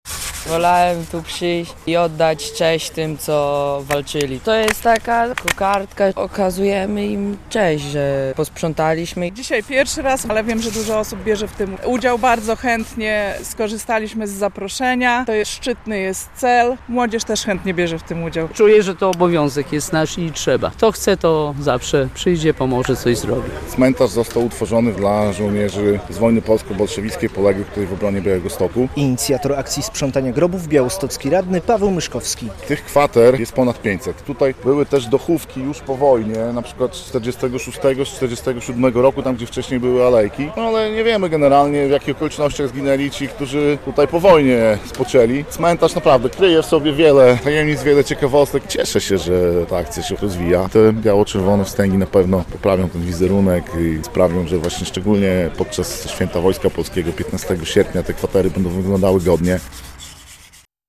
Sprzątanie grobów na cmentarzu wojskowym w Białymstoku - relacja